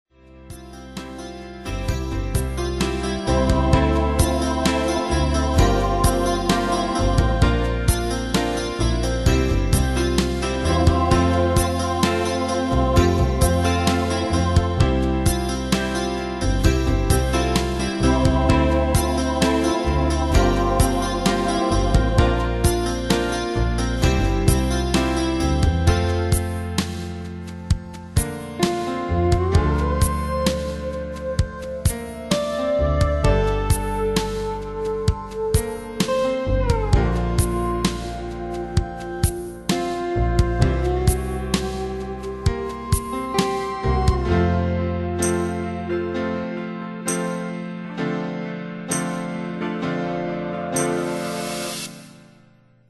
Demos Midi Audio
Style: PopFranco Année/Year: 2001 Tempo: 65 Durée/Time: 4.50
Danse/Dance: Ballade Cat Id.